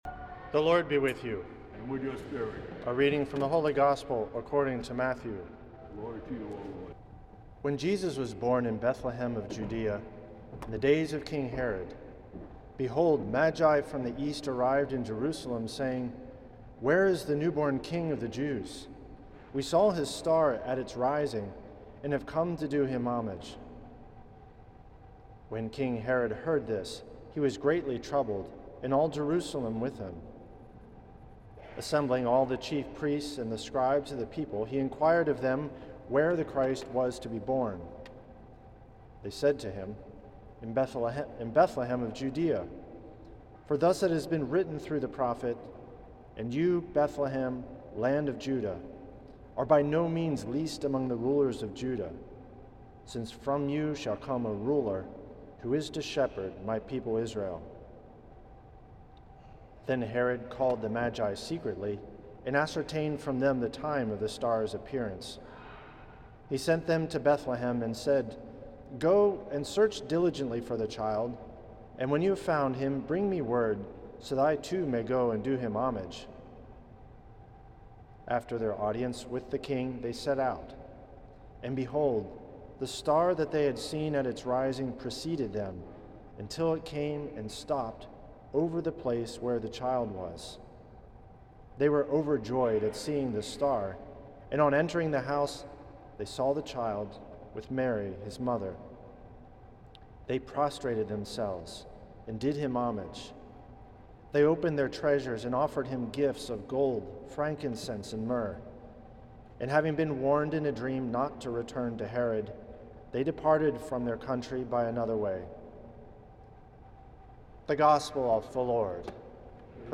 Homily
for January 7th, the Epiphany of Our Lord, at St. Patrick’s Old Cathedral in NYC.